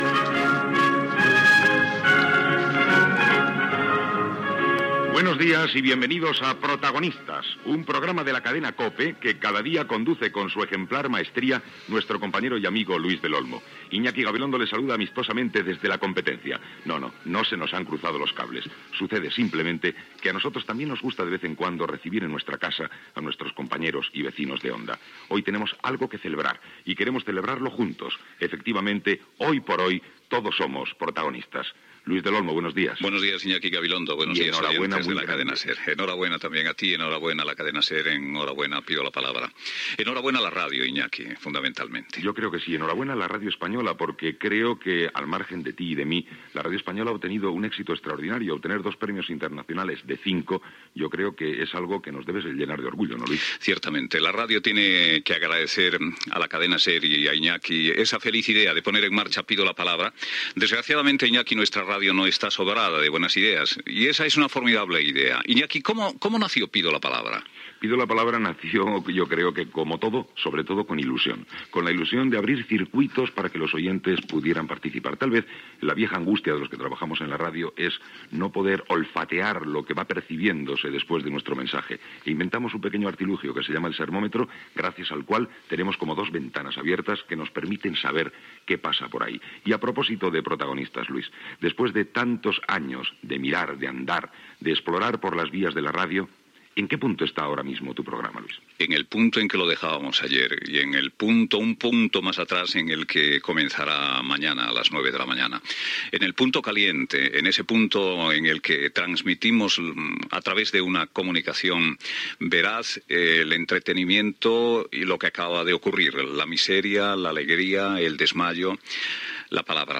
Connexió conjunta de Cadena SER i COPE per la concessió dels Premis Ondas als programes "Protagonistas" i "Pido la palabra"
Info-entreteniment